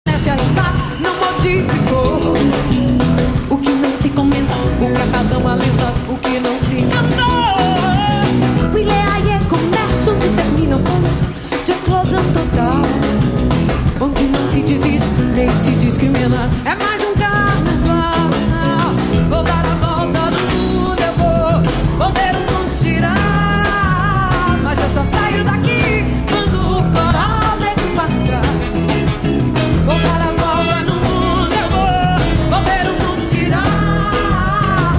Stuttgart, 2000